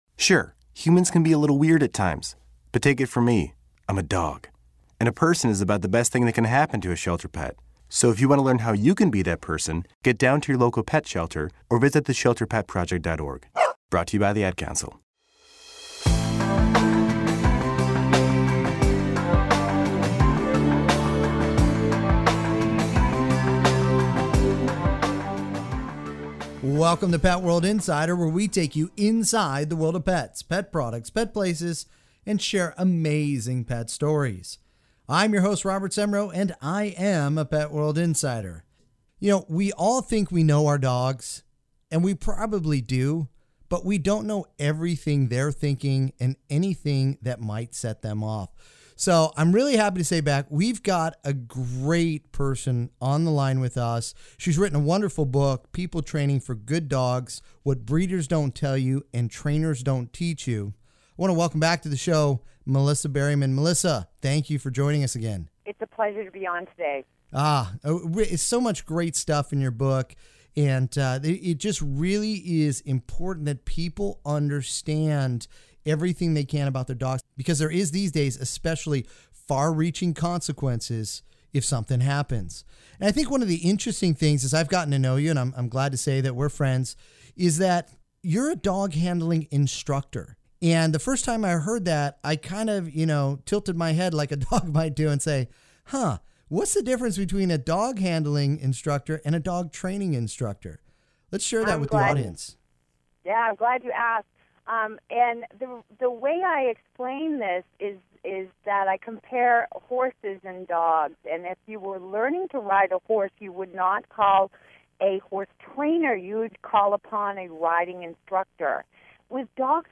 On this Pet World Radio Segment we sit down with an expert in the people and pet training world.
Enjoy this Pet World Radio Segment in case a station near you does not currently carry Pet World Radio on the EMB and CRN networks!